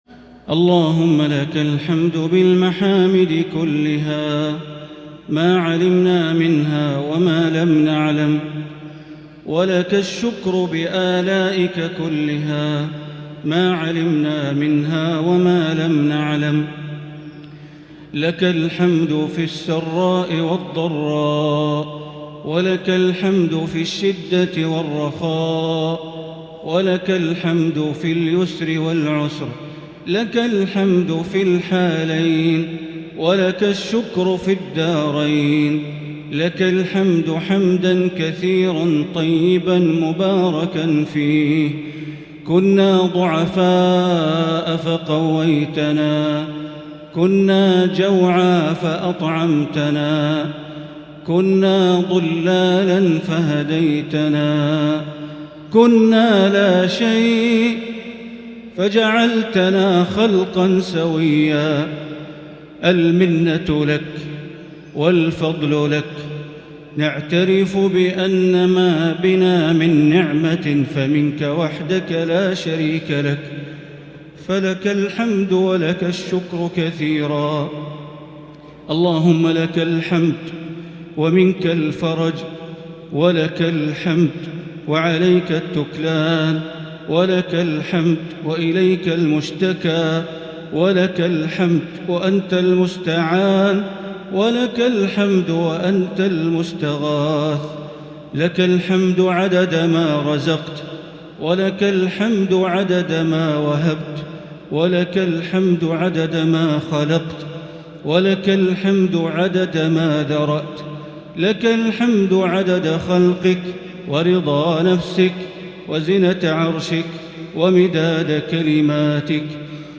دعاء القنوت ليلة 26 رمضان 1442هـ | Dua for the night of 26 Ramadan 1442H > تراويح الحرم المكي عام 1442 🕋 > التراويح - تلاوات الحرمين